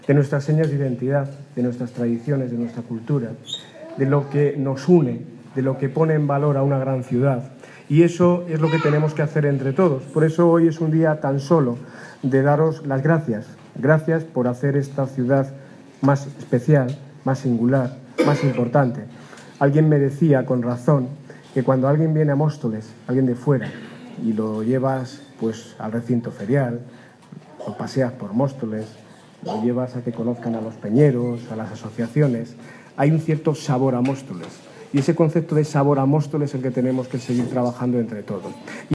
Audio de Daniel Ortiz, Alcalde de Móstoles